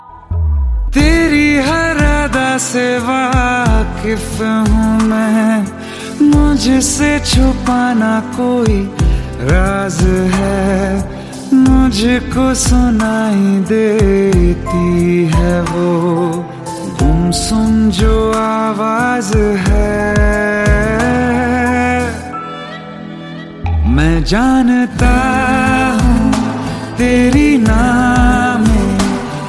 Ringtones Category: Bollywood